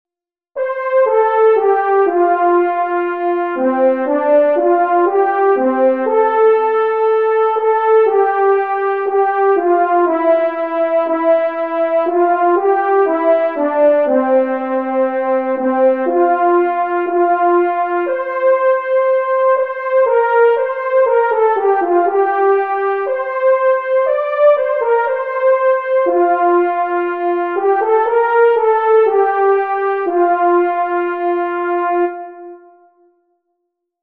sine_nomine_goto_unison.mp3